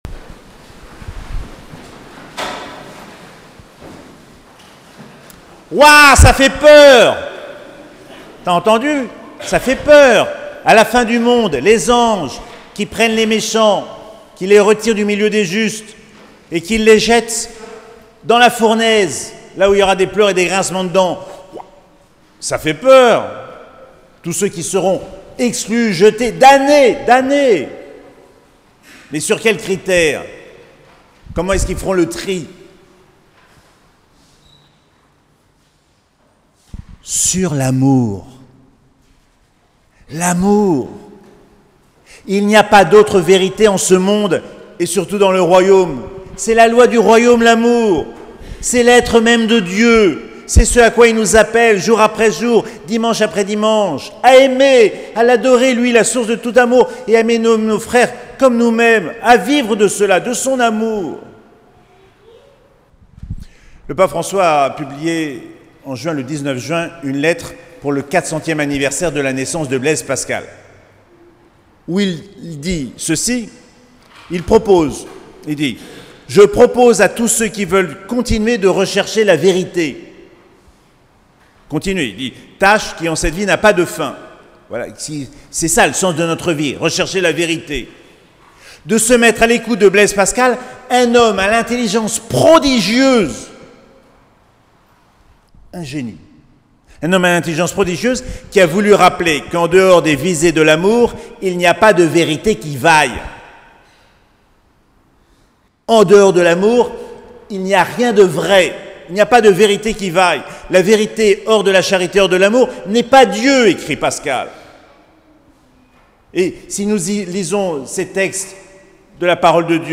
17ème dimanche du Temps Ordinaire - 30 juillet 2023